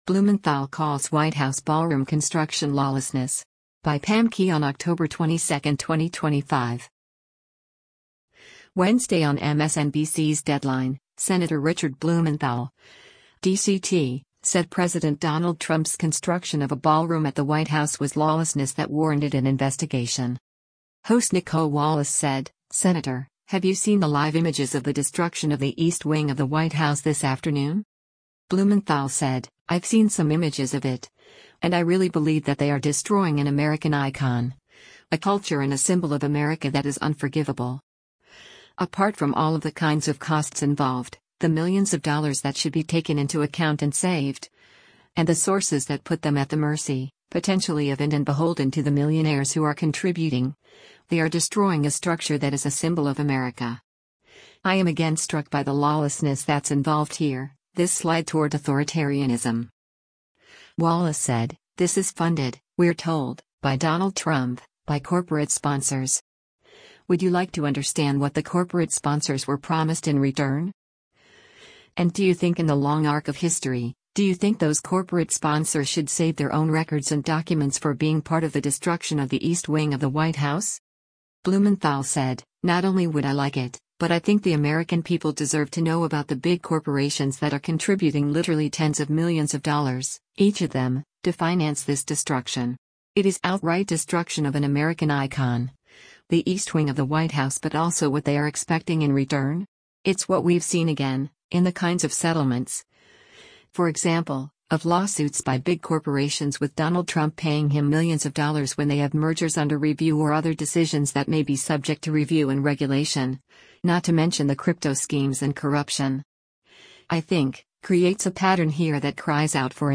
Host Nicolle Wallace said, “Senator, have you seen the live images of the destruction of the East Wing of the White House this afternoon?”